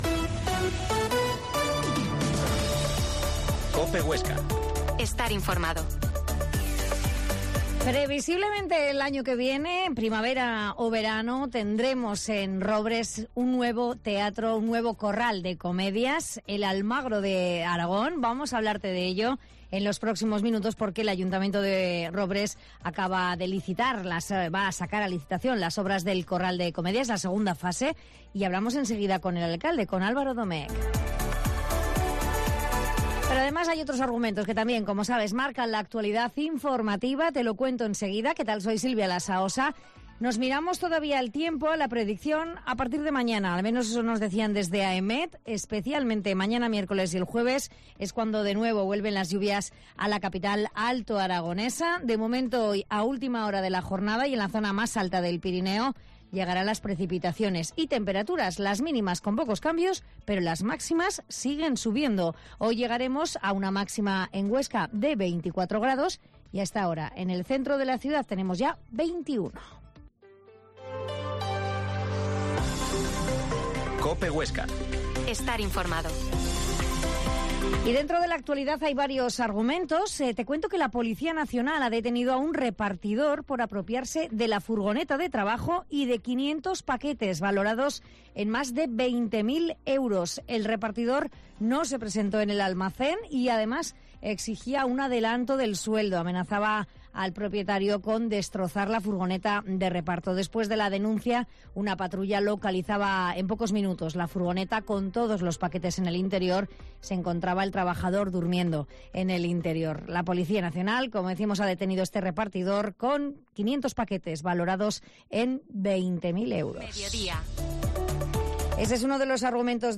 Mediodia en COPE Huesca 13.50 Entrevista al alcalde de Robres, Alvaro Domec